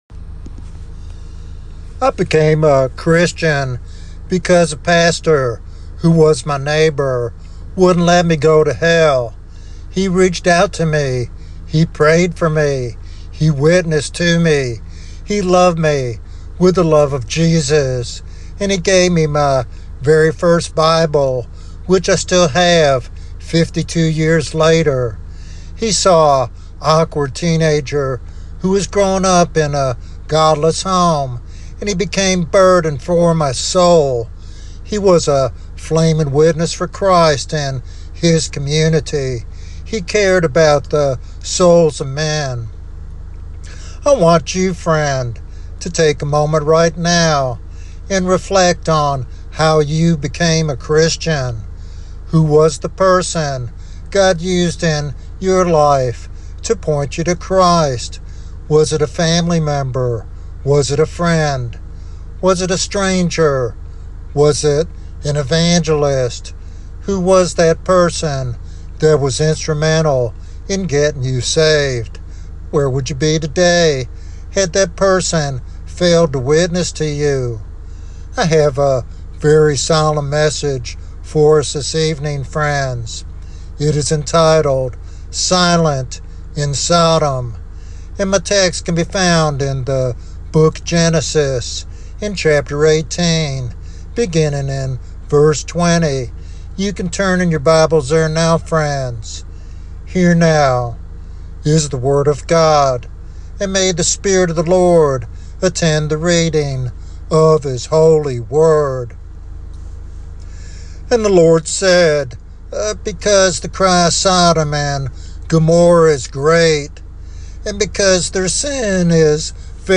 This sermon calls for self-examination and action to be a voice for righteousness in a world filled with sin and corruption.